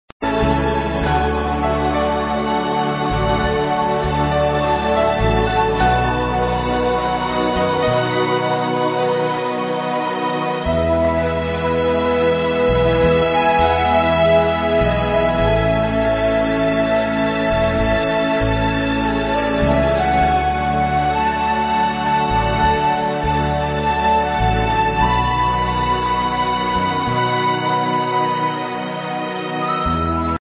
d�wi�kowych medytacji